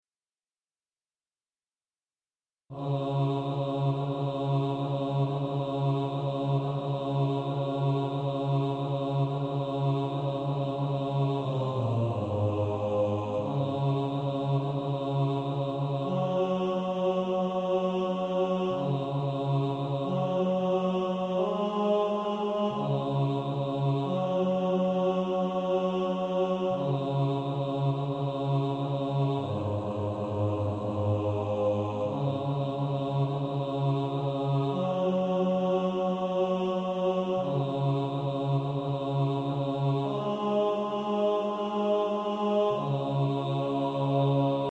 Bass Track.
Practice then with the Chord quietly in the background.